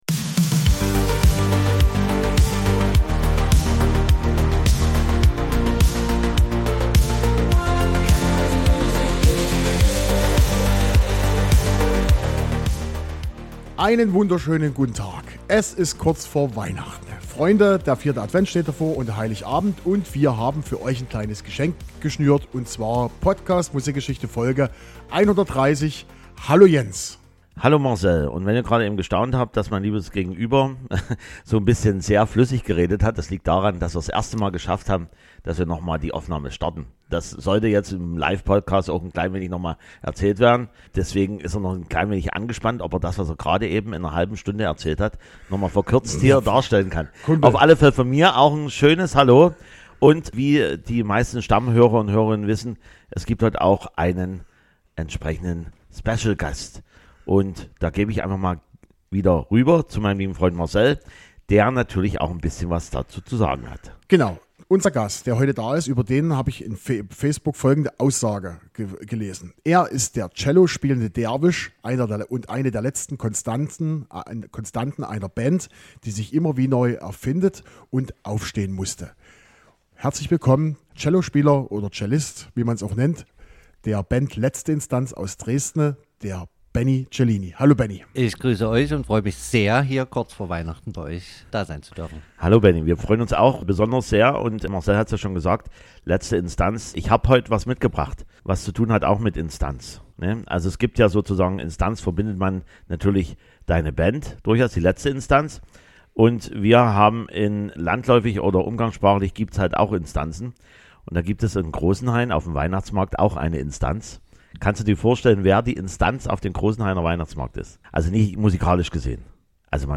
Ausführlich berichtet er über sein langes Musikerleben und seine Anfänge. Natürlich sprechen wir wieder über Musik aus den Charts und am Ende gibt es wieder Live-Musik.